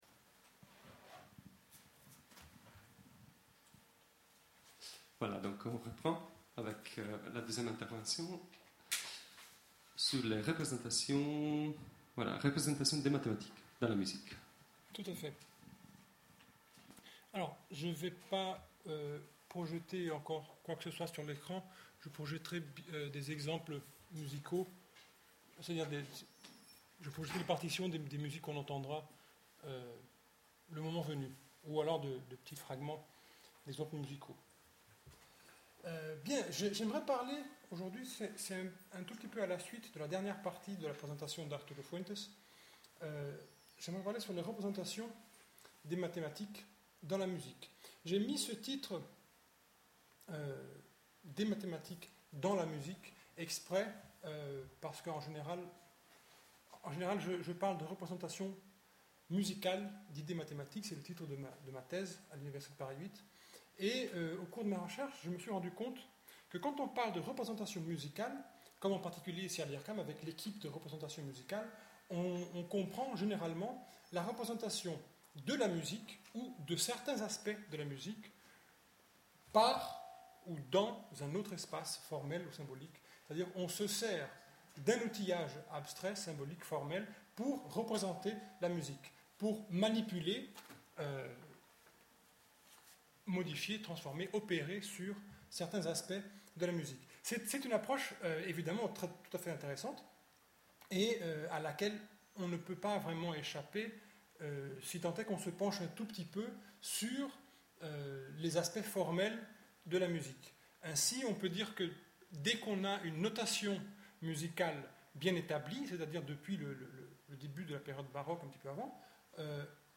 Ircam, Salle I. Stravinsky